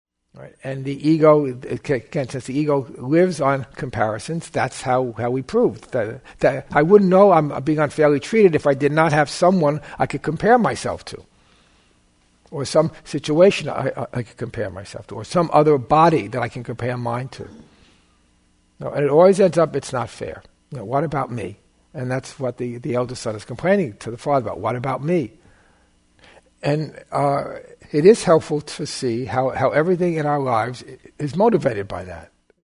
Perhaps the most famous of Jesus’ gospel parables, “The Prodigal Son” forms the basis of this workshop, which focuses on our need to feel ourselves unfairly treated. It is the brother of the returning prodigal son that epitomizes this favorite tactic of the ego’s dynamic of specialness: comparing oneself to others.